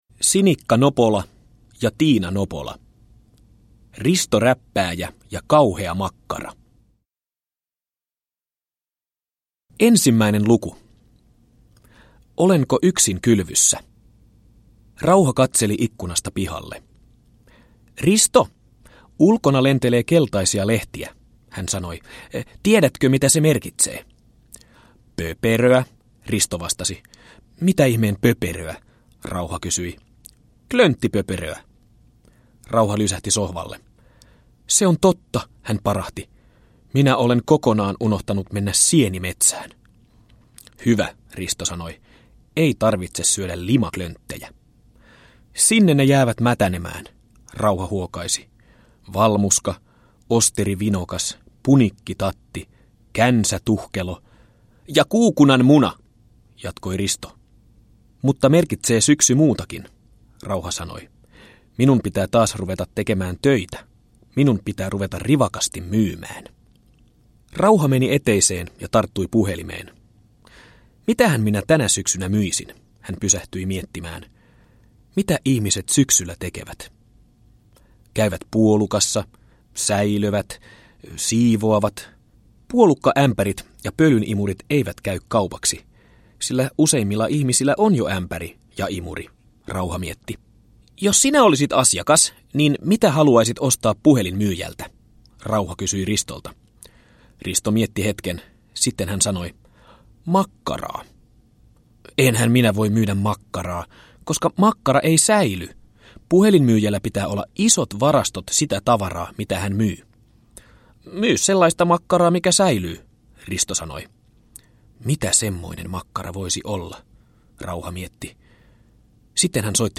Risto Räppääjä ja kauhea makkara – Ljudbok